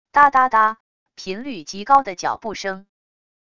嗒嗒嗒……频率极高的脚步声wav音频